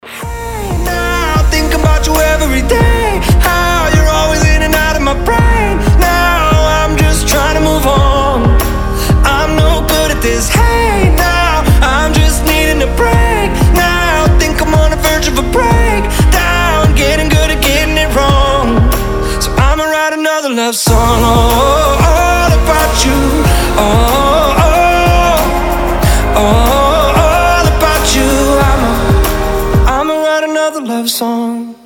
• Качество: 320, Stereo
красивый мужской голос
indie pop